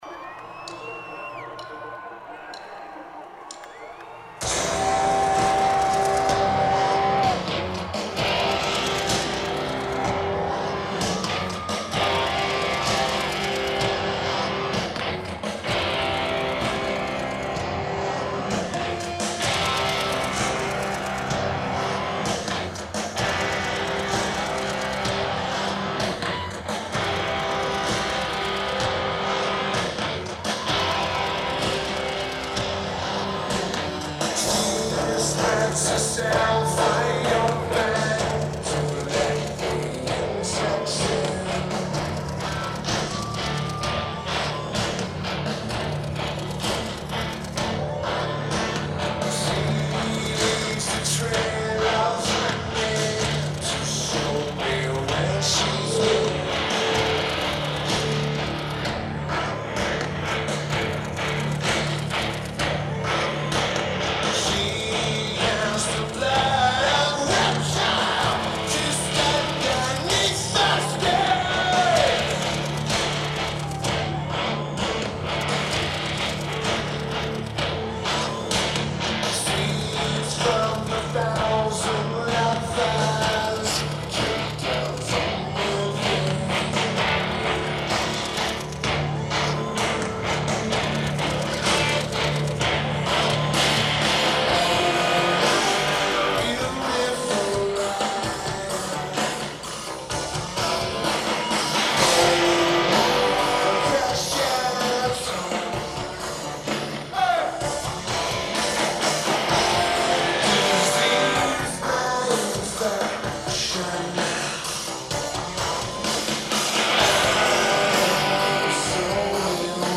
Red Rocks Amphitheatre
Lineage: Audio - AUD (Sony ECM-909a + Microtrack 24/96)